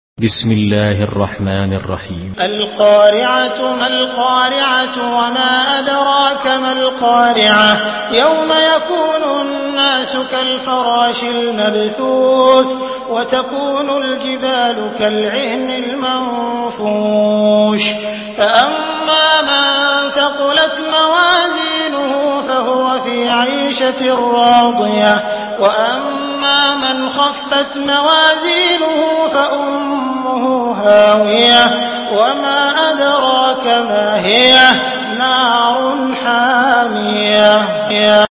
Surah Karia MP3 in the Voice of Abdul Rahman Al Sudais in Hafs Narration
Murattal Hafs An Asim